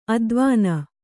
♪ advāna